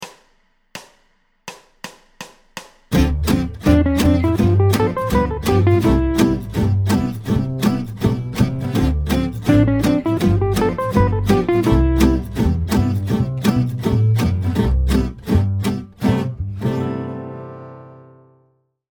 Après un chromatisme introductif, la phrase monte et descend la gamme de C Majeur